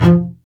STR BASS M1Z.wav